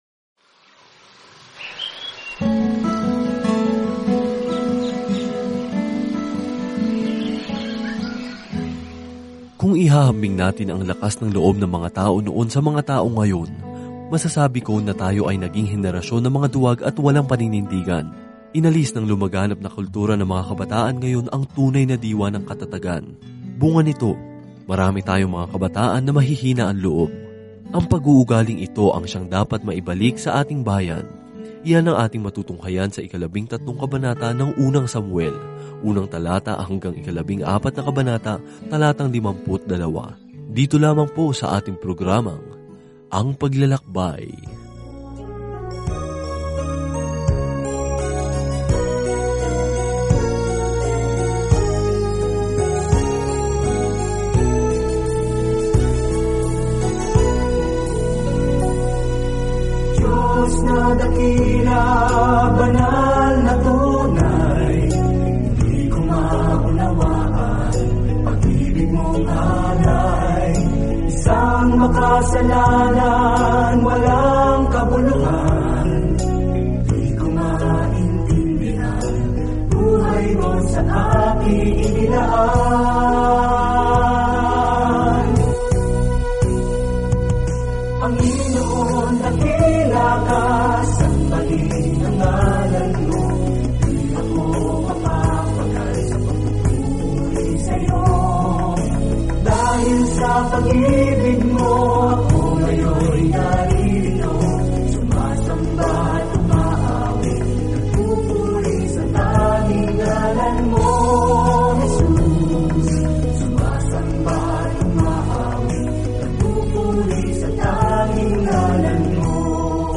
Banal na Kasulatan 1 Samuel 13 1 Samuel 14 Araw 6 Umpisahan ang Gabay na Ito Araw 8 Tungkol sa Gabay na ito Nagsimula ang Unang Samuel sa Diyos bilang hari ng Israel at nagpatuloy sa kuwento kung paano naging hari si Saul noon si David. Araw-araw na paglalakbay sa Unang Samuel habang nakikinig ka sa audio study at nagbabasa ng mga piling talata mula sa salita ng Diyos.